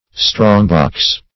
strongbox \strong"box`\, n.